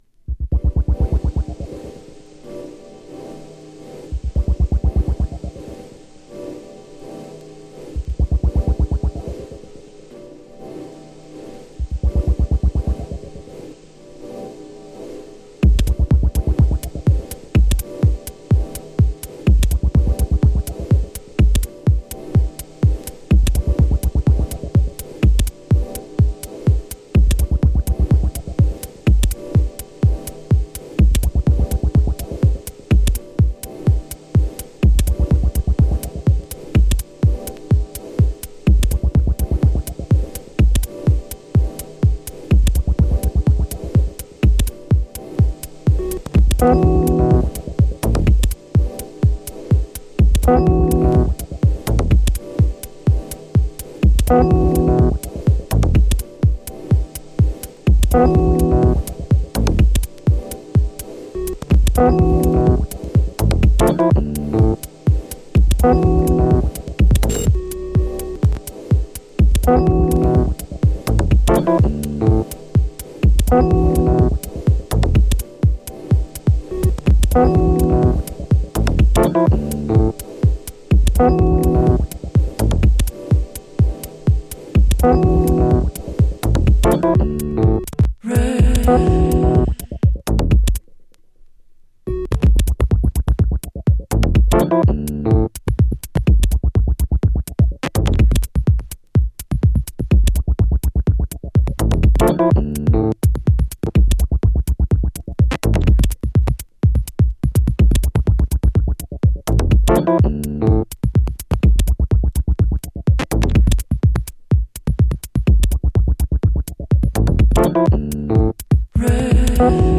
Voici un pti mix maison pour bouger son cul ou s'isoler du monde au boulot.
Sa minimal est fine et délicate, pas que boom boom. Y'a aussi des bleep, sss, chik, et plein de trucs malins déboulés de son imagination fertile.